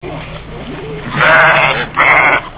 sheep.wav